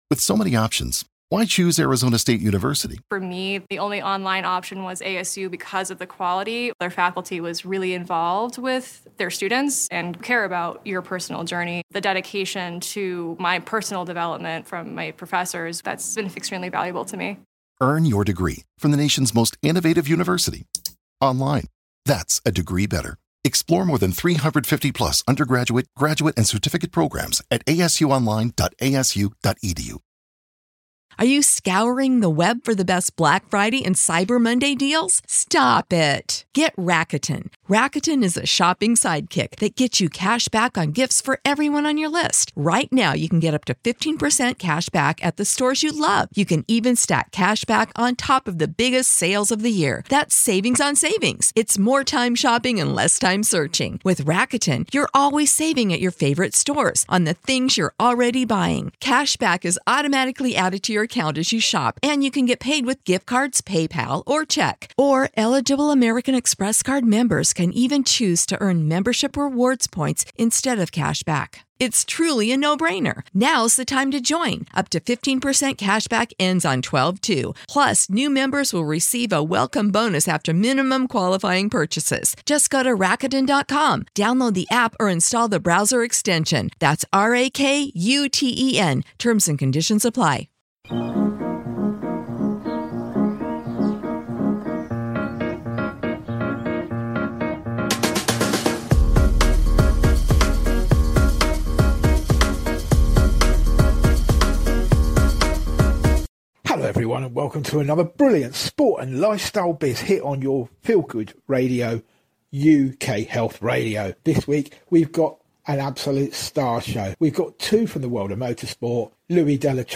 Sports and Lifestyle Biz is a fast, informative and action-packed show bringing you all from the world of sport with a hint of lifestyle and biz. We will bring you the best from all the major sporting news and events including Football, Formula 1, Rugby and Cricket to the extreme sports like Chess Boxing. We will bring you features on youth foundations and the development areas for youngsters plus interviews with inspirational women and children.